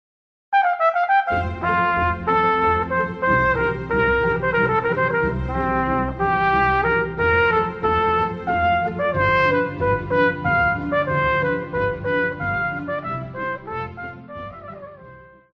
Classical
Trumpet
Orchestra
Instrumental
Only backing